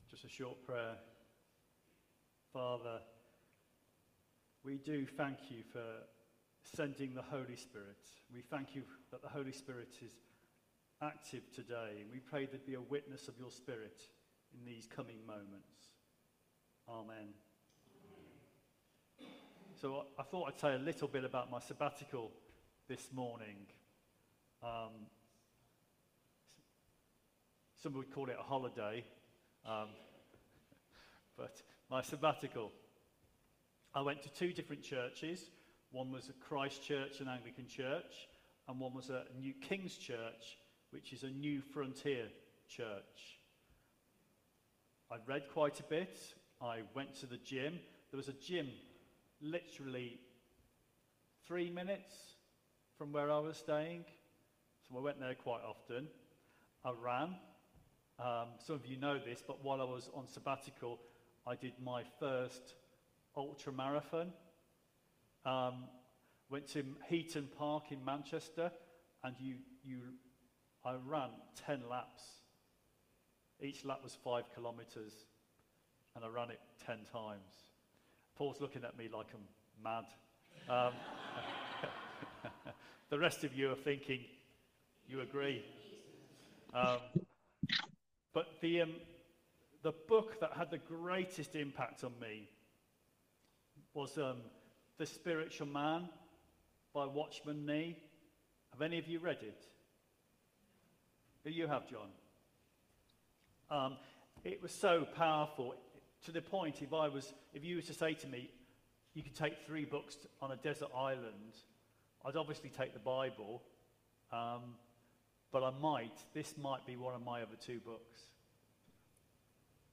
Theme: Live out of the Spirit Sermon Search